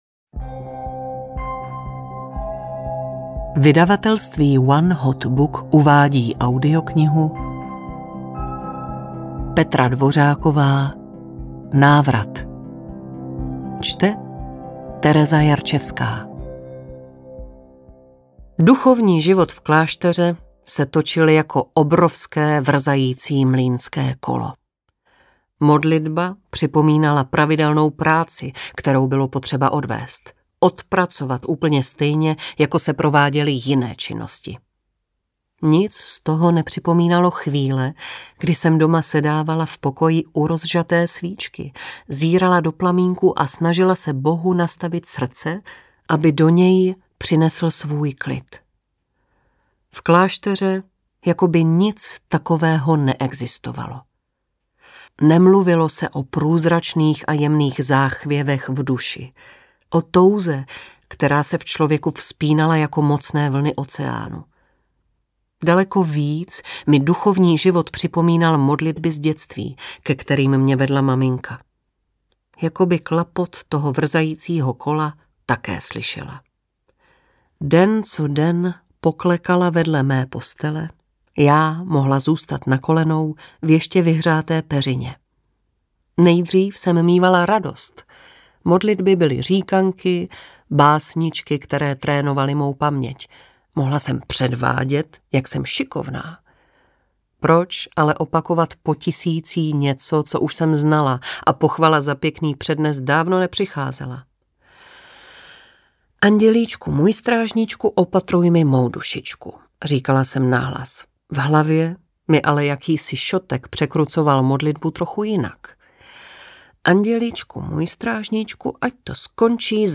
Návrat audiokniha
Ukázka z knihy